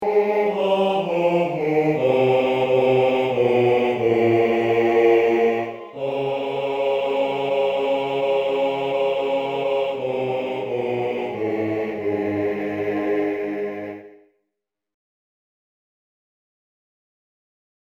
Key written in: A♭ Major
How many parts: 4